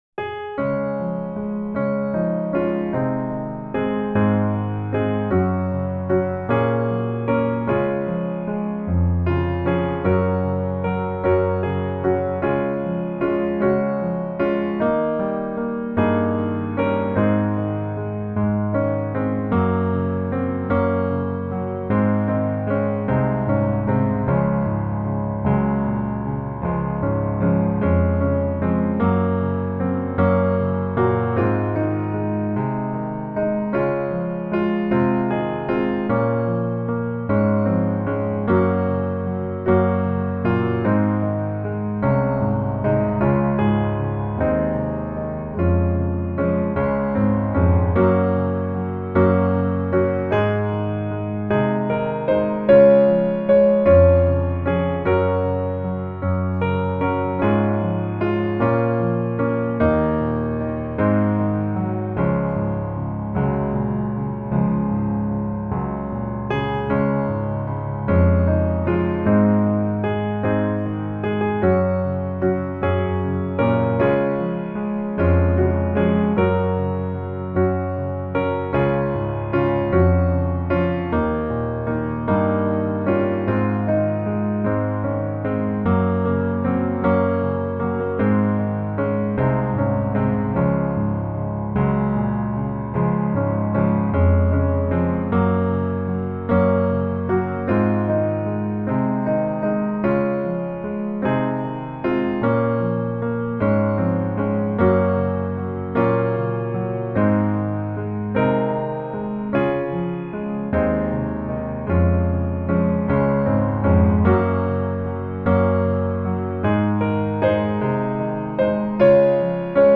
Db Major